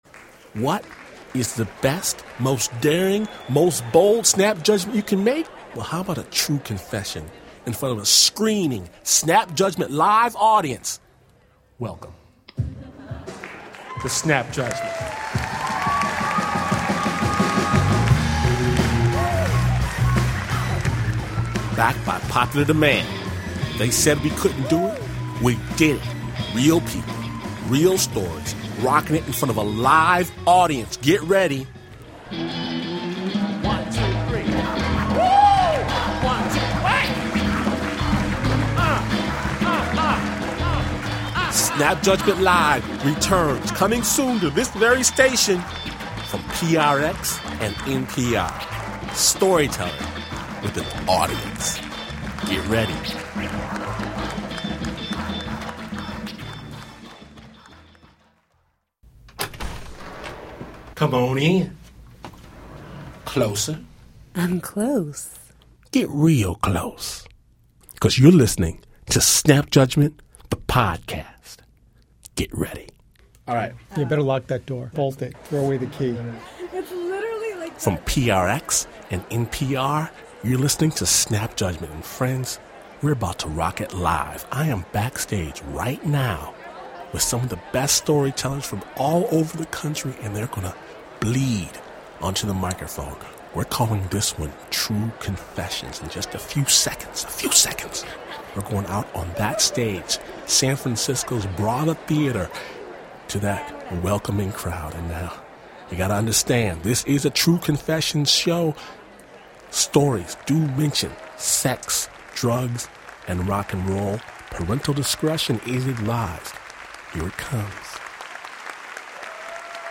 Storytellers from across the nation reveal their true confessions to a theater audience for the third episode of Snap Judgment LIVE. Glynn Washington hosts the courageous confessors as they unveil their darkest secrets at San Francisco's Brava Theater.